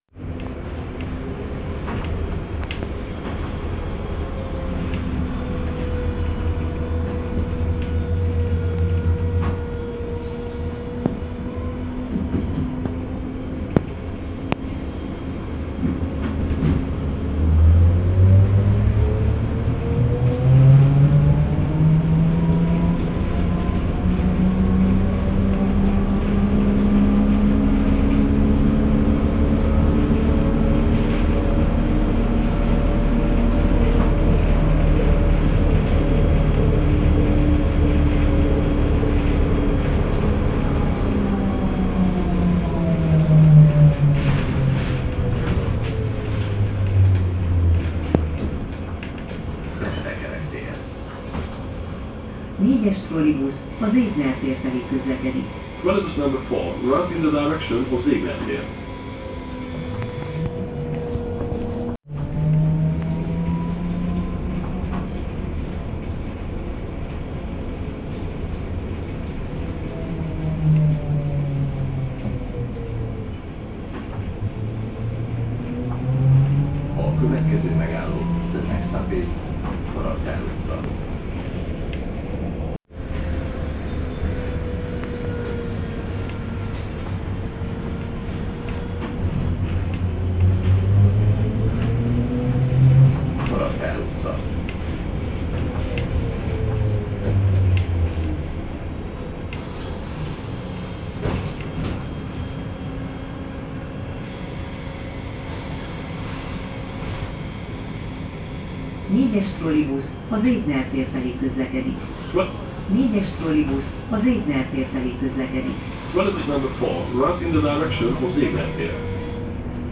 Új hangfelvétel készült reggel a 4-es vonalon közlekedő #402-esről. Van még mit fejleszteni a bemondásokon (a hangerő már megfelelő egyre több járművön, viszont pl. a Kandia utcát be se mondja...)